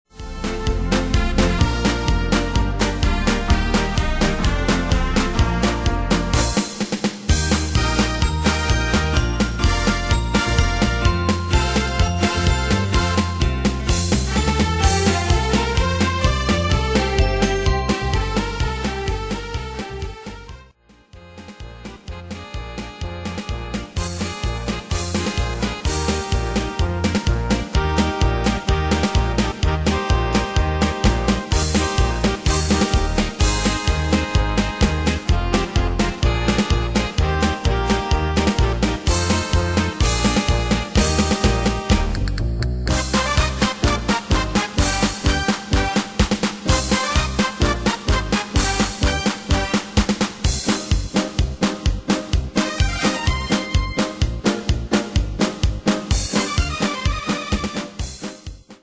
Demos Midi Audio
Style: Medley Année/Year: 2007 Tempo: 127 Durée/Time: 5.47
Danse/Dance: Farandole Cat Id.
Pro Backing Tracks